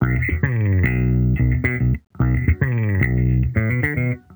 Index of /musicradar/sampled-funk-soul-samples/110bpm/Bass
SSF_JBassProc2_110B.wav